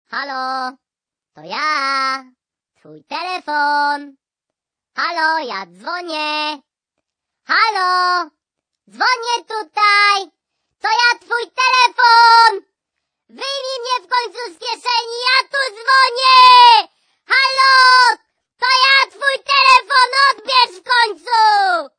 Kategorien Telefon